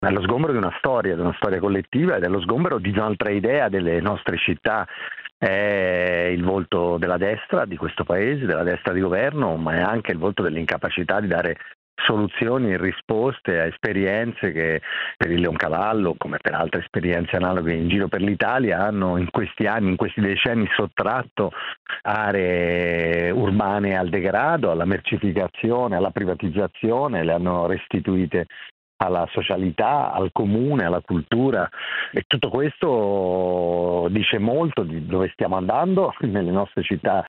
Sentiamo Nicola Fratoianni, segretario di Sinistra italiana: